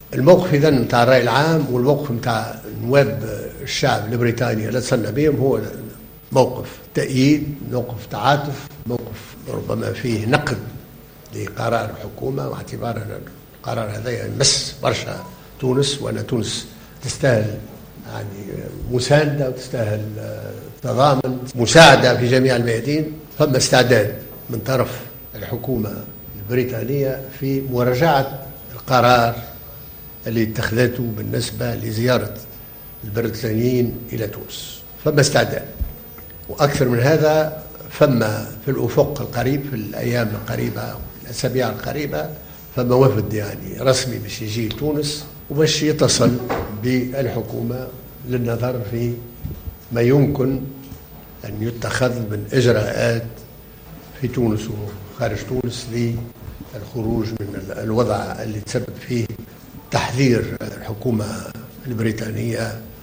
واعتبر في ندوة صحفية عقدها بمقر البرلمان أن زيارة الوفد الرسمي التونسي إلى بريطانيا ناجحة والتي جاءت على اثر هجوم سوسة الإرهابي يوم 26 جوان الماضي، والذي أدى إلى مقتل 30 سائحا بريطانيا.